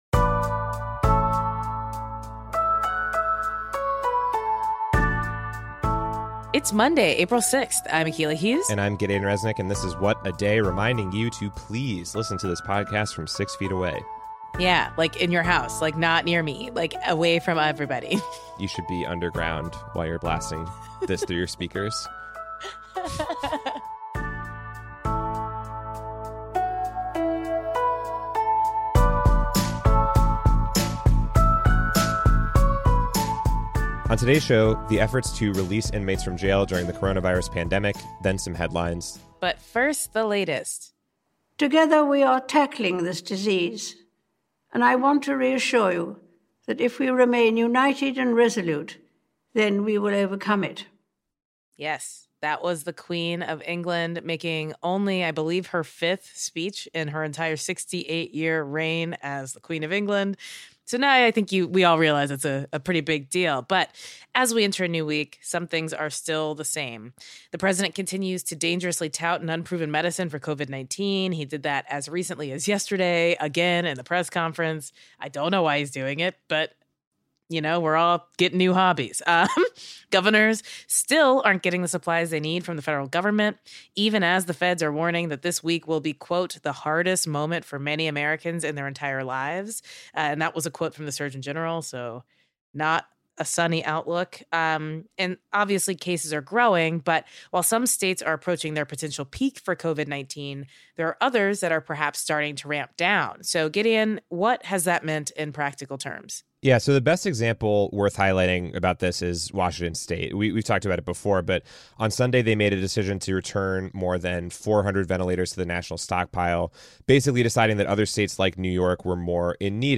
We interview